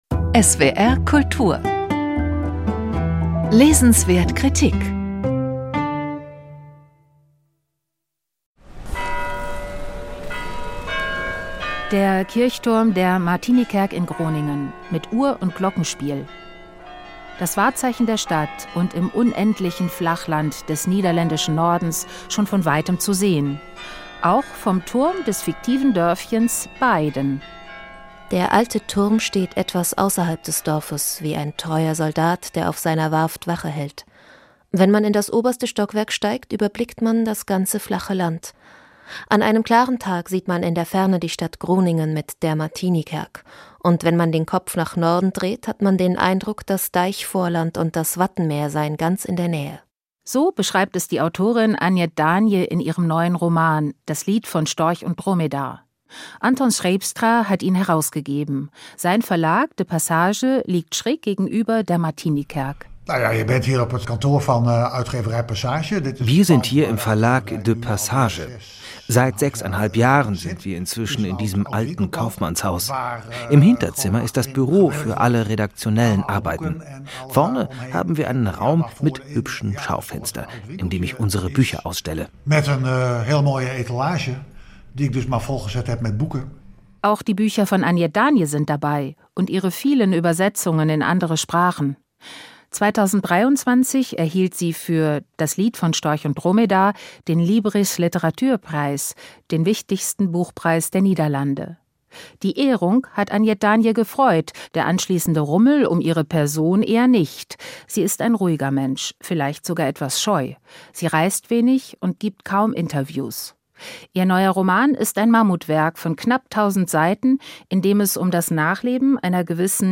Besuch bei der Autorin in Groningen.